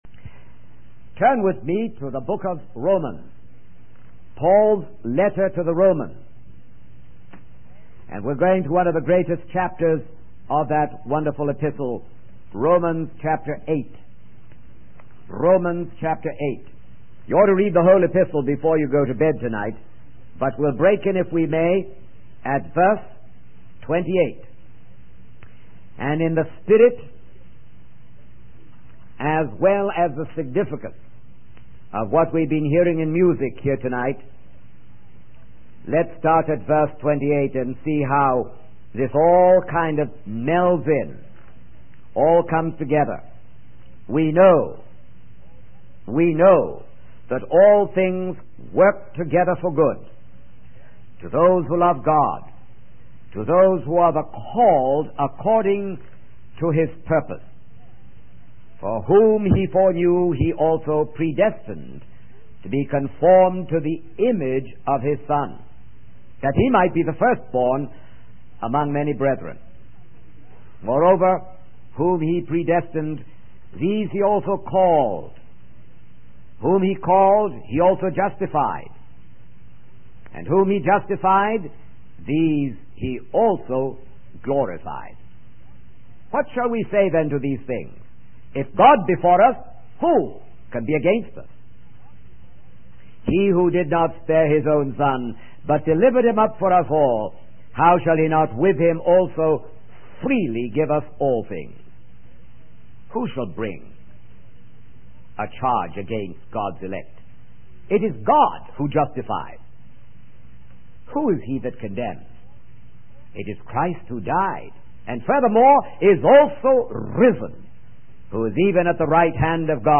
In this sermon, the preacher recounts the story of Paul's conversion on the Damascus road.